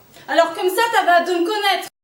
On entend ce que l'on voit